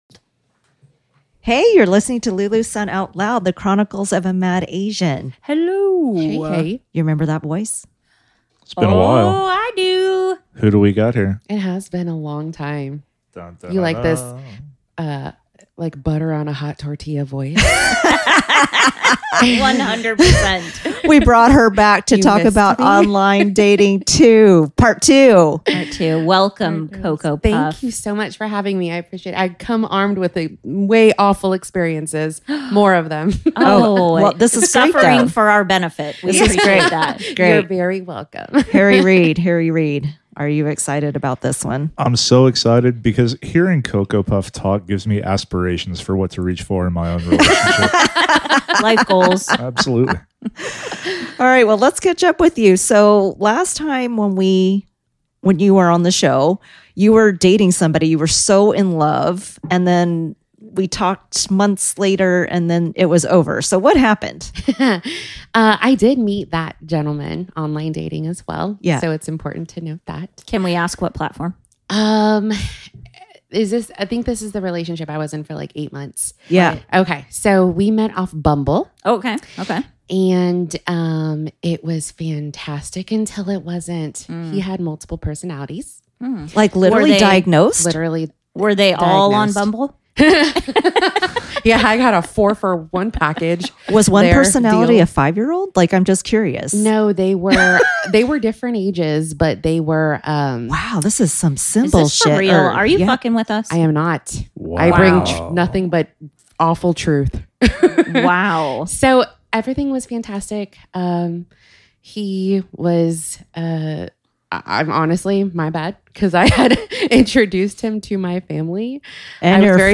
This blooper is what we sound like behind the scenes.